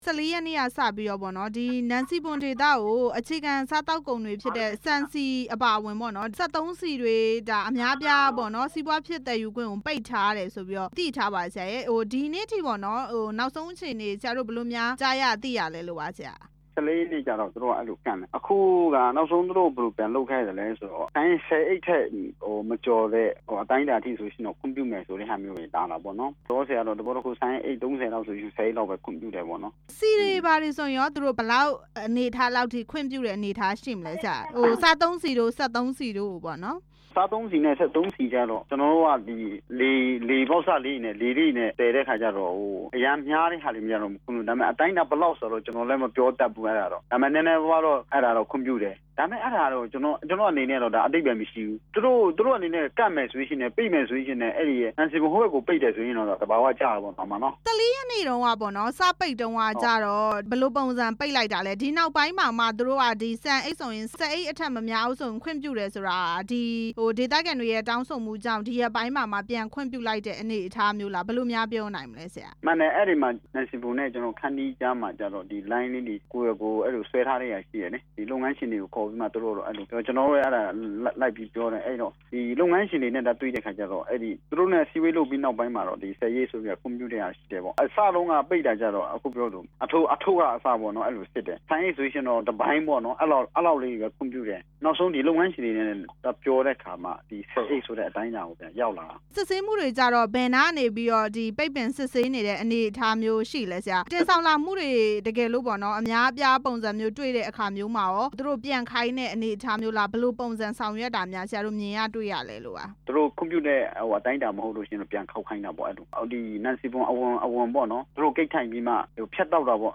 နန်စီပွန်ဒေသ စားနပ်ရိက္ခာ သယ်ယူခွင့်အကြောင်း မေးမြန်းချက်